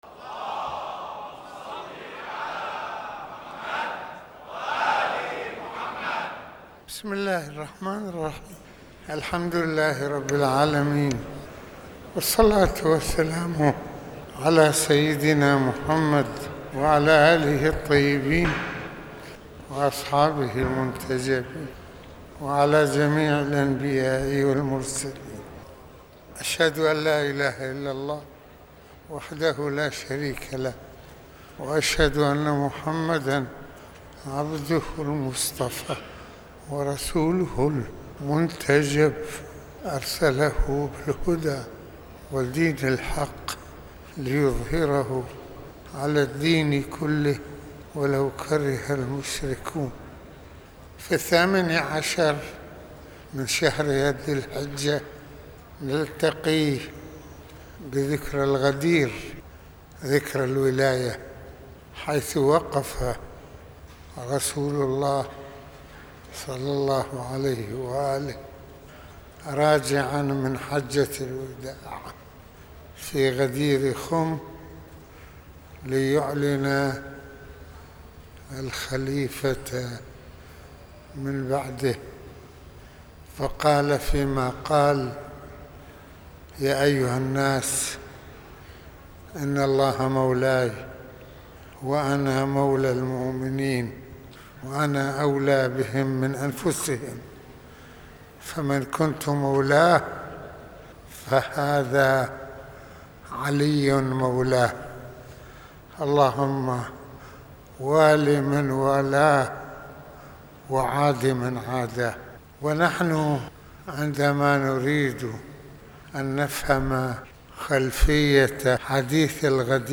- المناسبة : خطبة الجمعة
المكان : مسجد الإمامين الحسنين(ع)